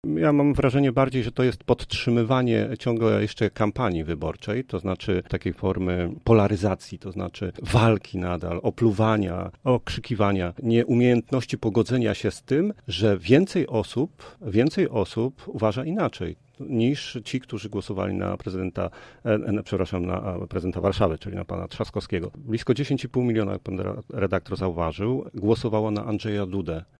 To kolejna forma walki – uważa przewodniczący zielonogórskiej Rady Miasta, który był gościem Rozmowy Punkt 9: